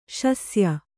♪ śasya